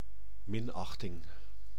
Ääntäminen
IPA : /kənˈtɛmpt/